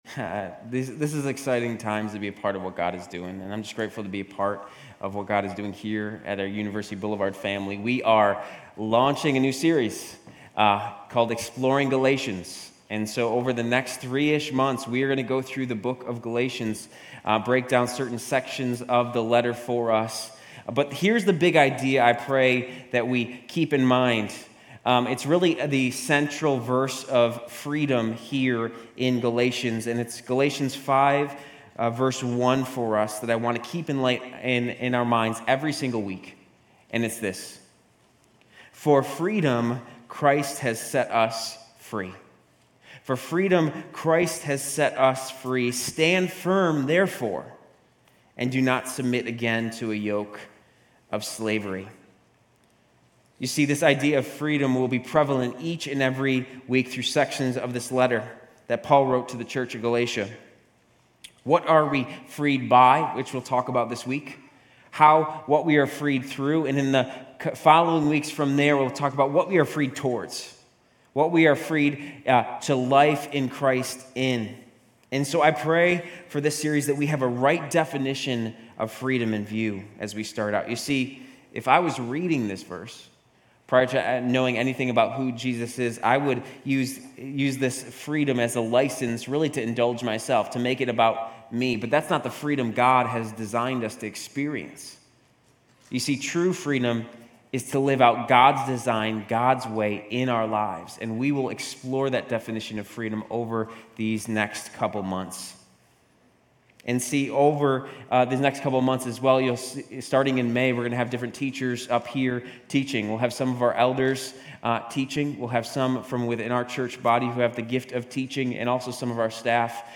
Grace Community Church University Blvd Campus Sermons Galatians 1:1-24 Apr 08 2024 | 00:29:29 1x 00:00 / 00:29:29 Subscribe Share RSS Feed Share Link Embed